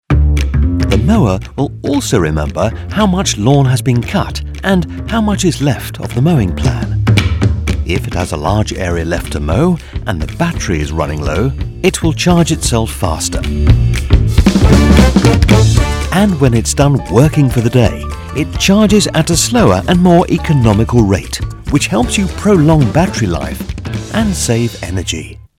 Older Sound (50+)
Warm, articulate British voice with natural authority and clean, confident delivery.
Explainer Videos